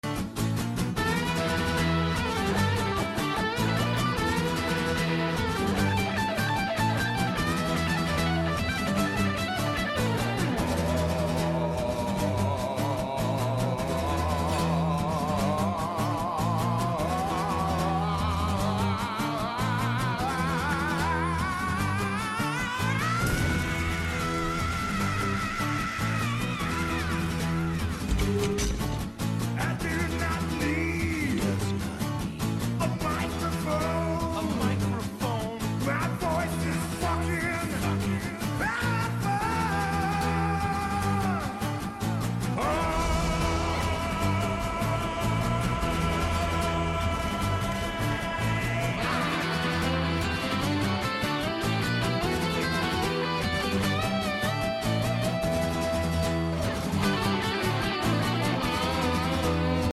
Guitar Cover